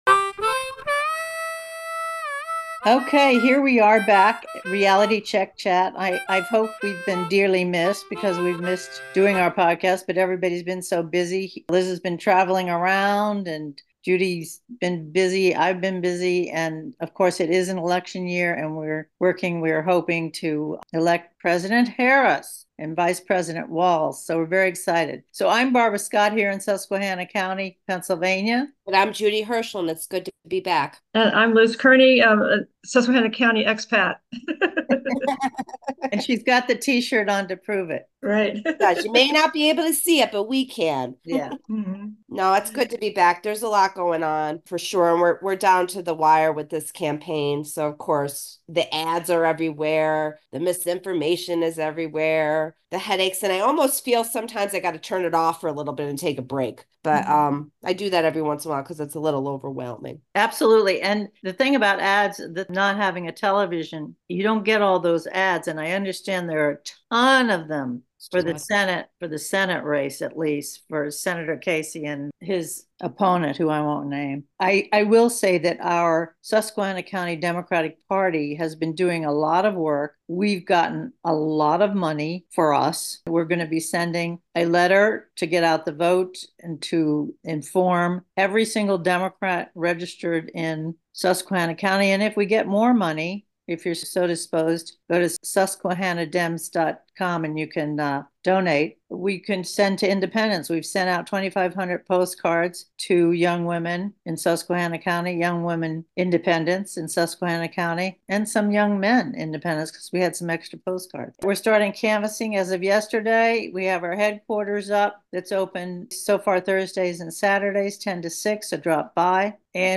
Three women Democrats discuss Rural and National Politics